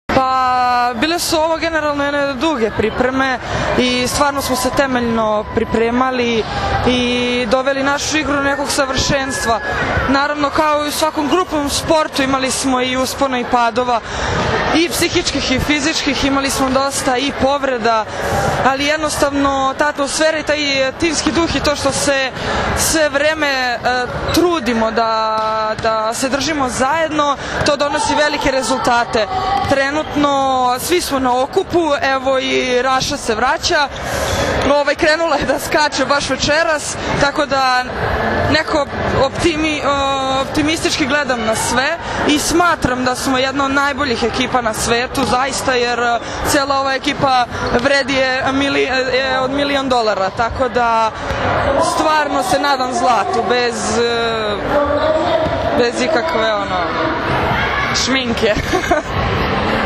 IZJAVA JOVANE STEVANOVIĆ